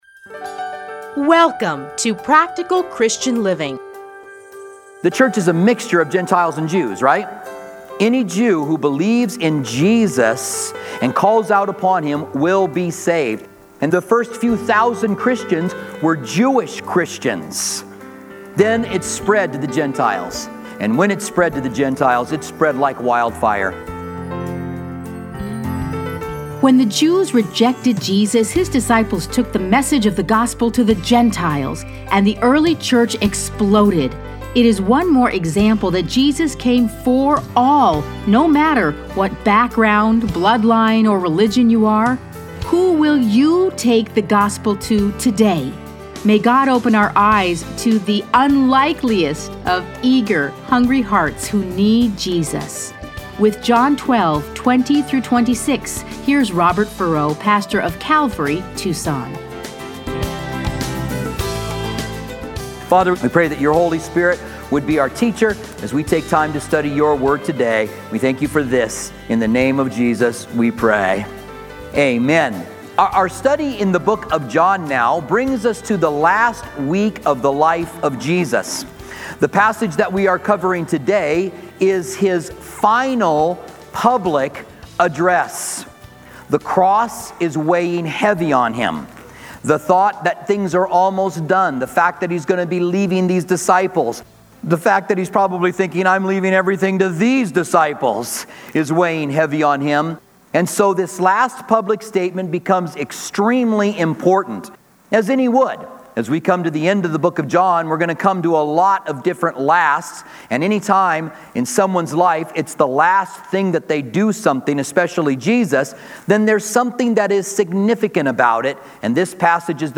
Listen to a teaching from John 12:20-26.